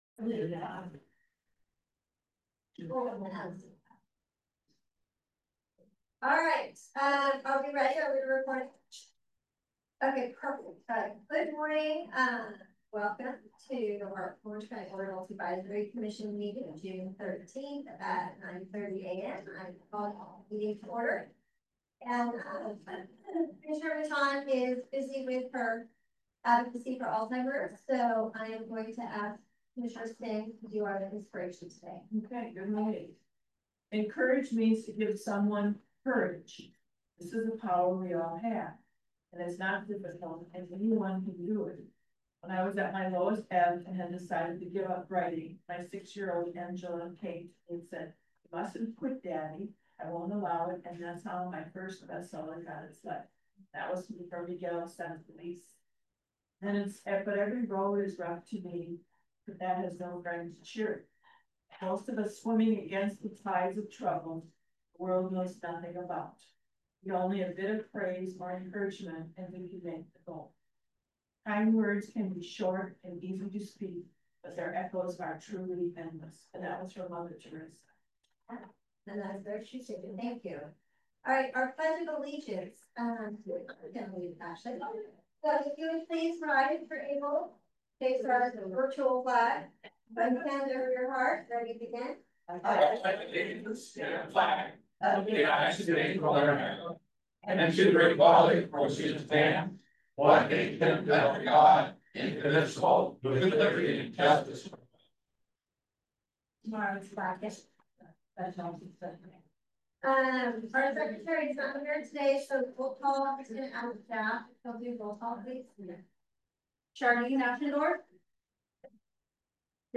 OAAC General Meeting Recording 6.13.25.mp3